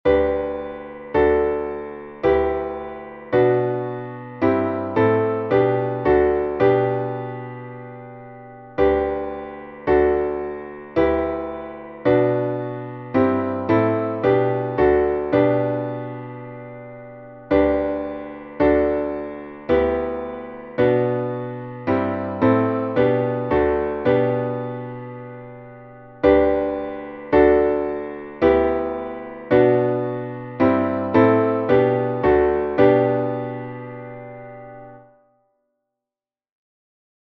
Traditional Choral